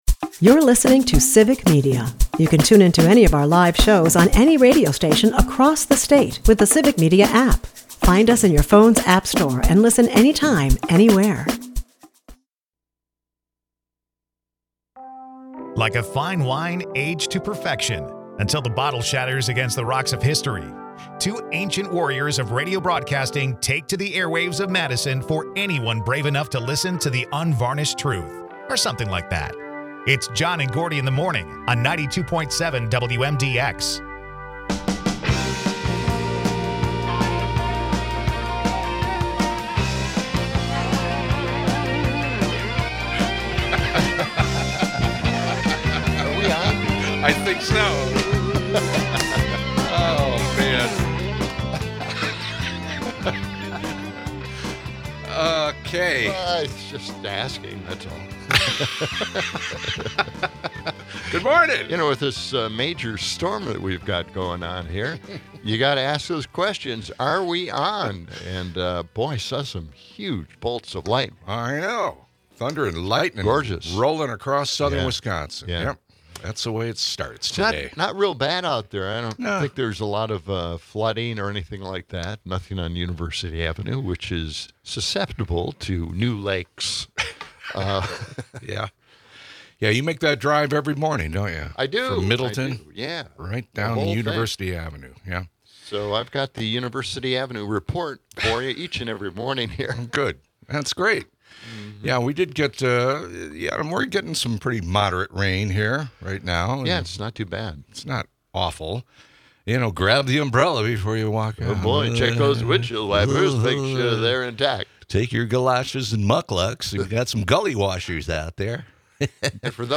Taylor Swift's surprise album drop shifts the conversation to pop culture. The episode takes a political turn with discussions on Trump's authoritarian tendencies, tariffs hurting small businesses, and ICE's new eye-scanning tech. Audience participation adds fuel to fiery debates about America's future.